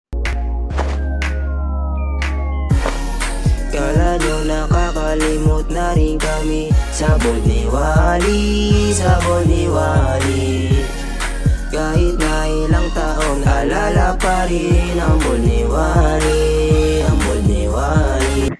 Bold ni Wally meme soundboard clip with funny, exaggerated voice for chaotic and viral reaction edits.